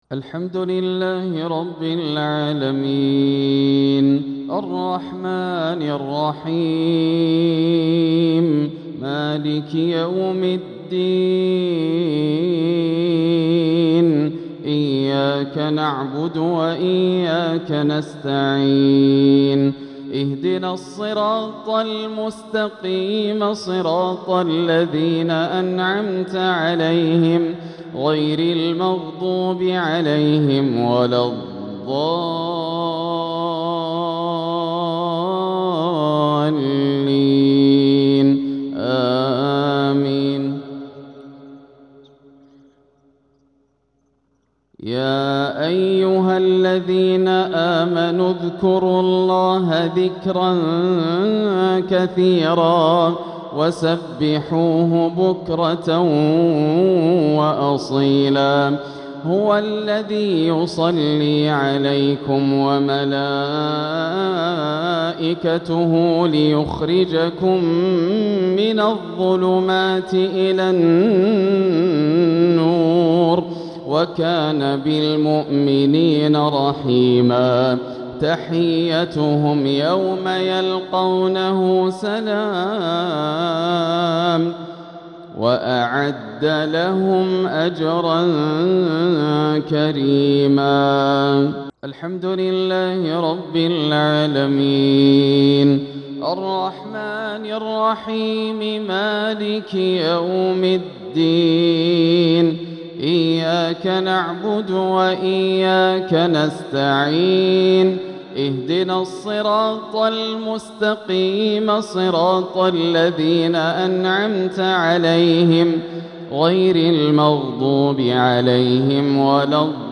تلاوة من سورة الأحزاب | مغرب الخميس 6-2-1447هـ > عام 1447 > الفروض - تلاوات ياسر الدوسري